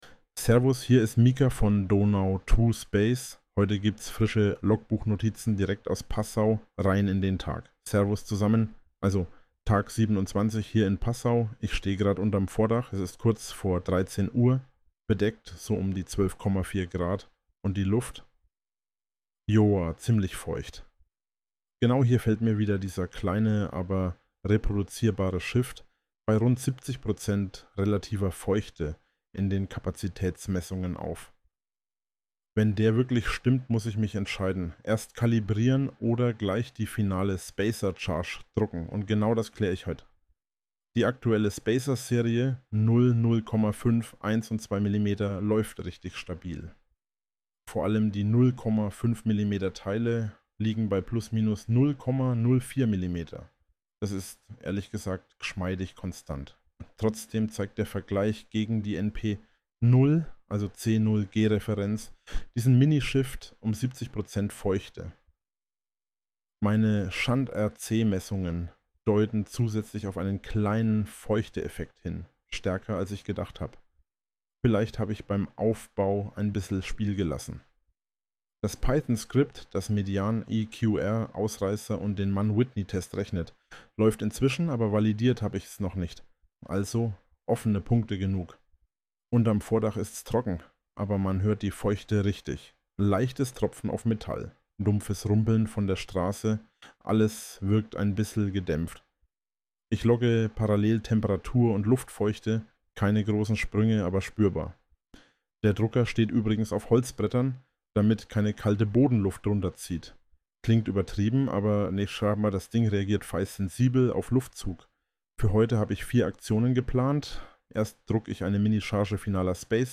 Hinweis: Dieser Inhalt wurde automatisch mit Hilfe von KI-Systemen (u. a. OpenAI) und Automatisierungstools (z. B. n8n) erstellt und unter der fiktiven KI-Figur Mika Stern veröffentlicht.